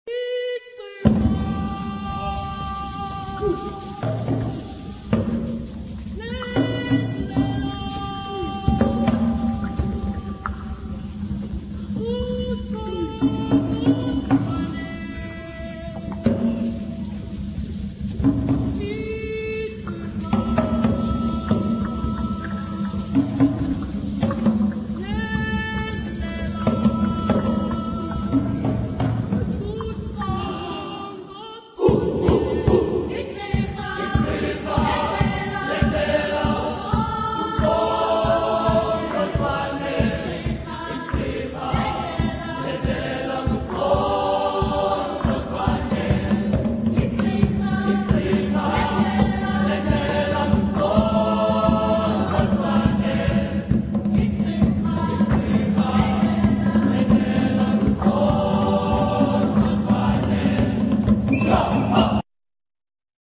Genre-Style-Form: Folk music ; Traditional
Mood of the piece: playful
Type of Choir: SATB  (4 mixed voices )
Instrumentation: Percussion  (1 instrumental part(s))
Instruments: Xylophone (1)
Tonality: G major